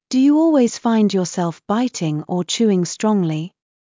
ﾄﾞｩ ﾕｰ ｵｰﾙｳｪｲｽﾞ ﾌｧｲﾝﾄﾞ ﾕｱｾﾙﾌ ﾊﾞｲﾃｨﾝｸﾞ ｵｱ ﾁｭｰｲﾝｸﾞ ｽﾄﾛﾝｸﾞﾘｰ